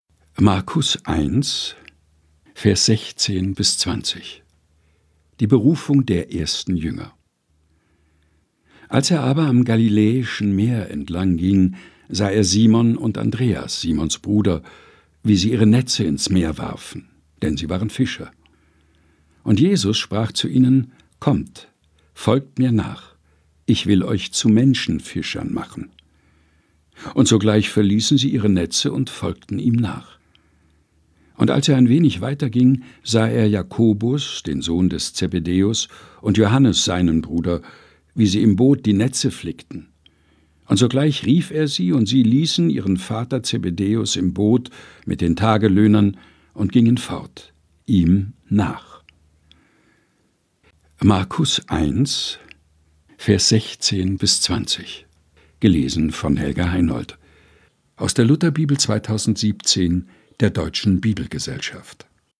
Texte zum Mutmachen und Nachdenken